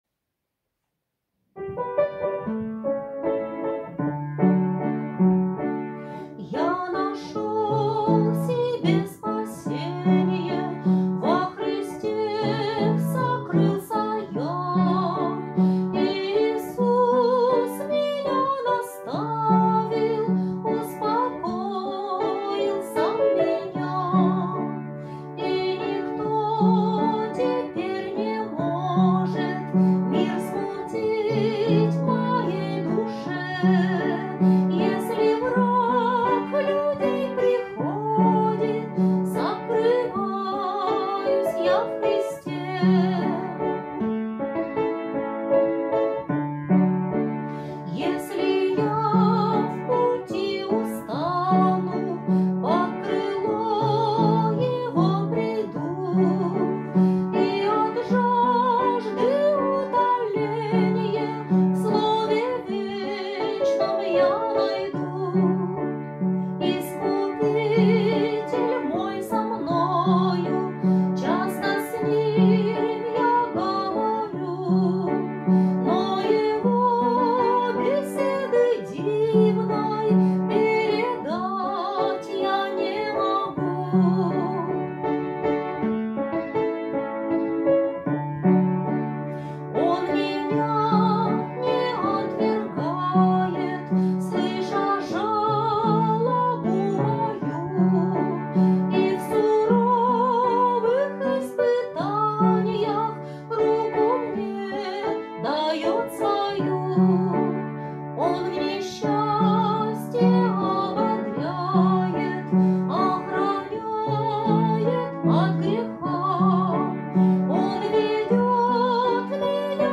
1514 просмотров 153 прослушивания 18 скачиваний BPM: 77